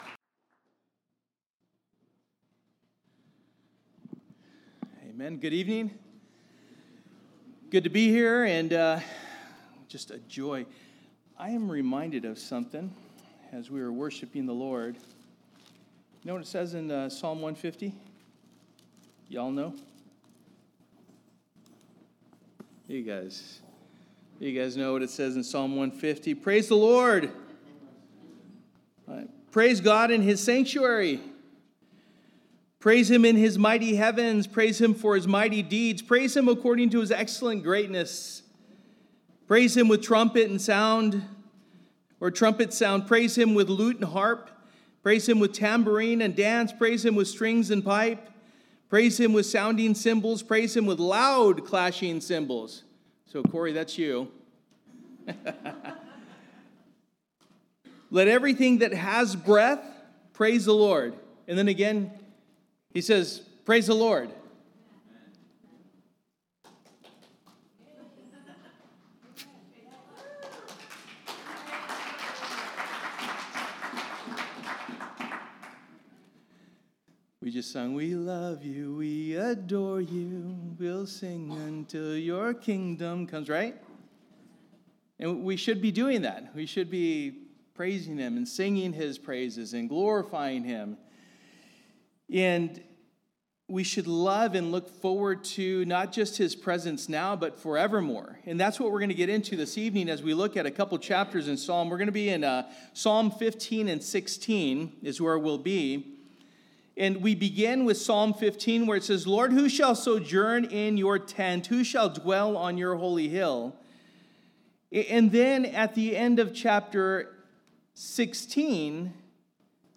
Through the Bible Passage: Psalms 15:1-16:11 Service: Wednesday Night « Blessed is the King Who Comes in the Name of the Lord!